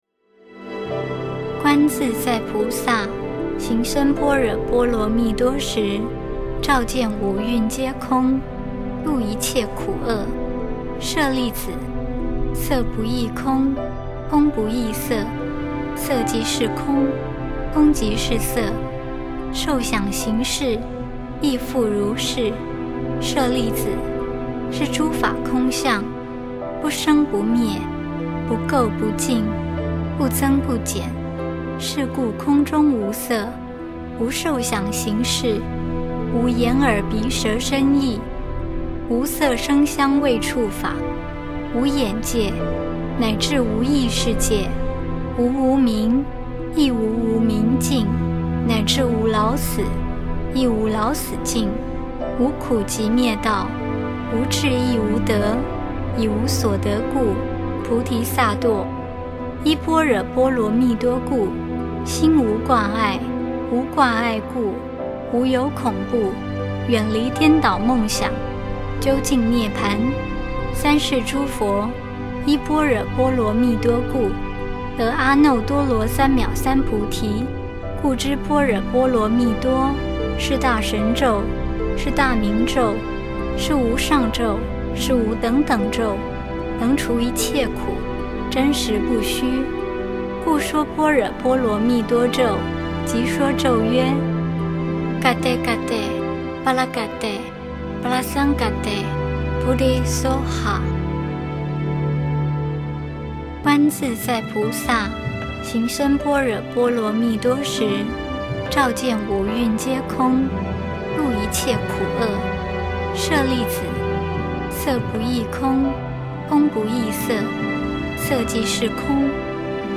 心經mp3唸誦版